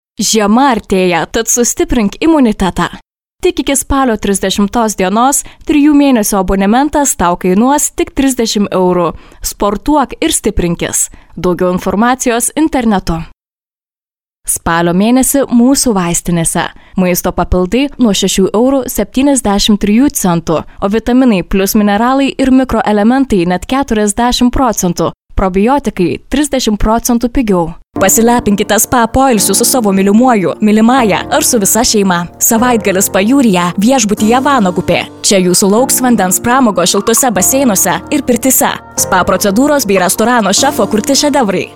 Литовские дикторы, литовская озвучка | „Baltic Voice“